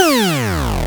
gameover3.wav